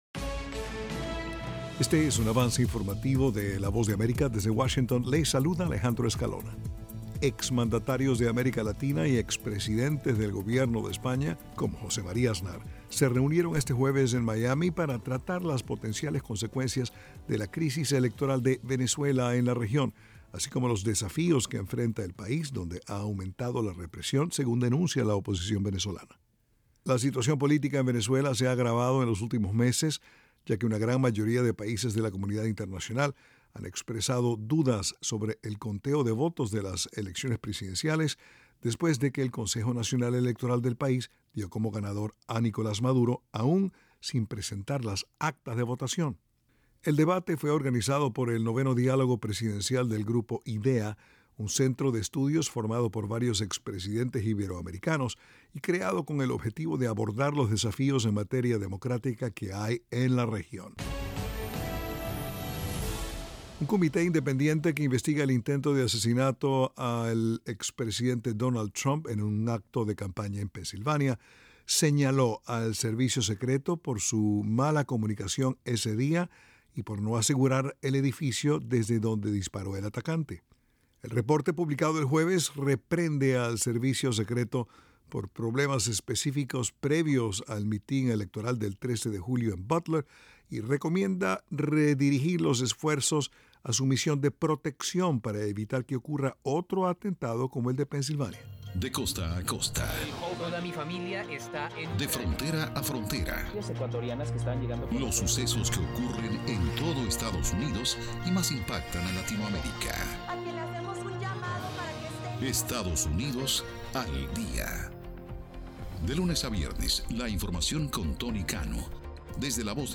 Avance Informativo
Este es un avance informativo presentado por la Voz de América en Washington.